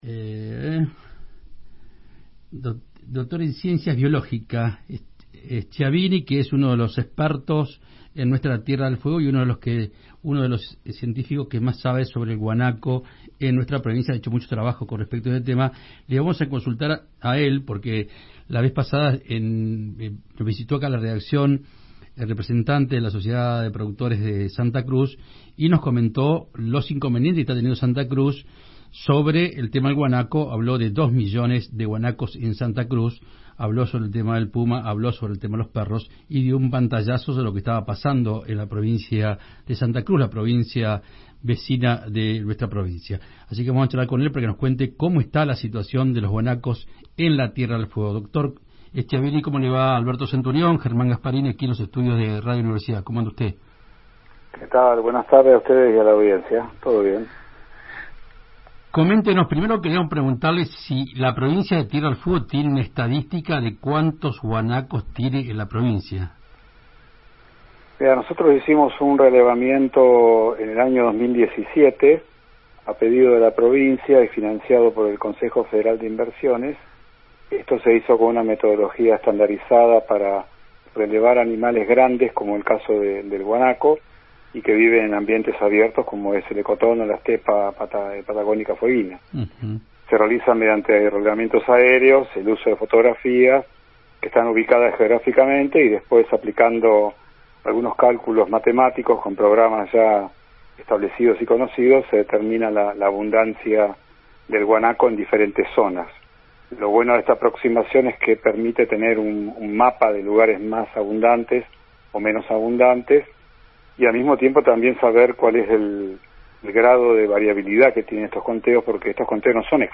dialogó con Radio Universidad 93.5 y Provincia 23